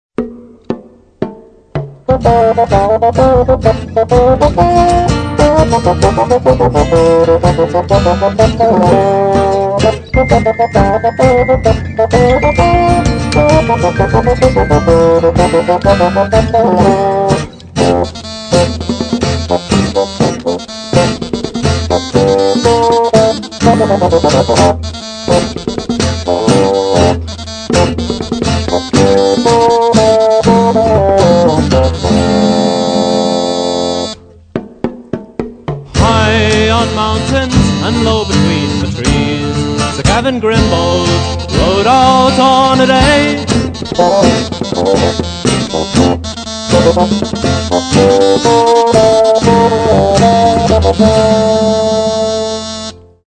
Stereo, 0:53, 56 Khz, (file size: 365 Kb).